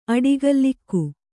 ♪ aḍigallikku